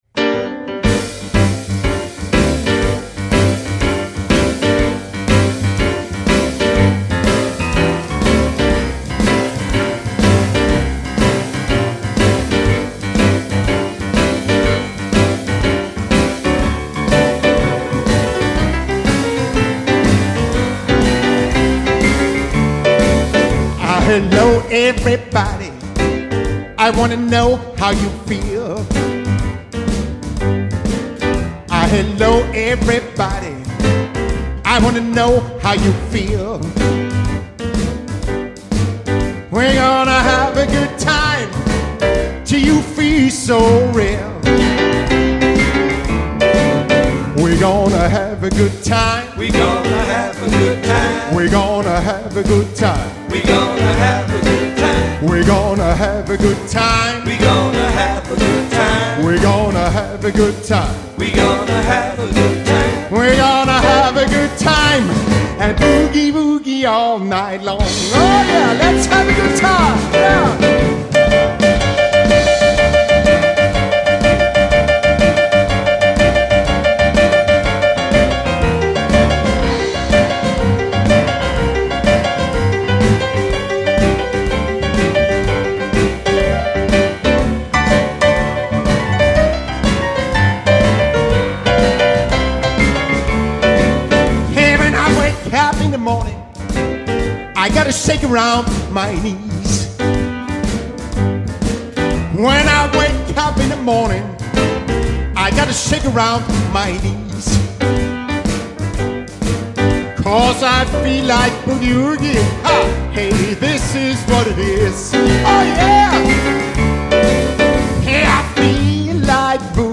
• Includes pure unadulterated boogie woogie and blues.
• I play on a Steinway and Sons "B" grand piano
p/voc/d/b